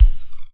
kick mono wav :